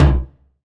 KICK - MATTER.wav